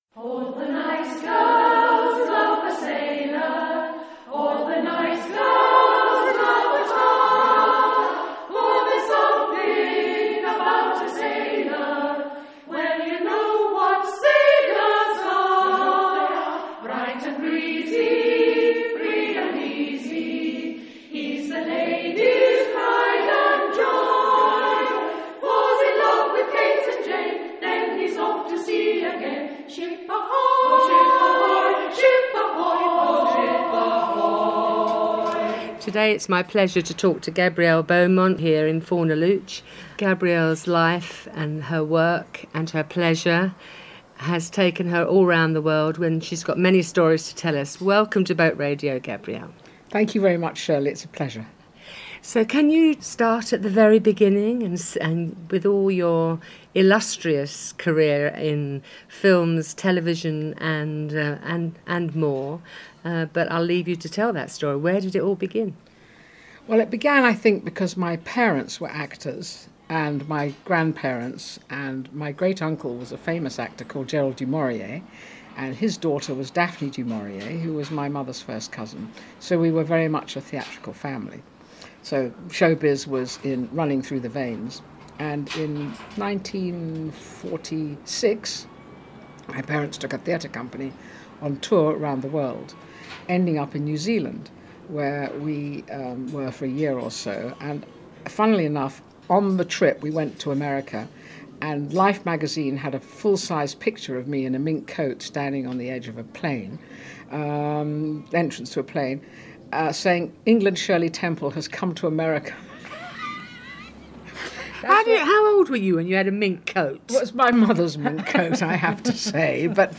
on the terrace of her hillside home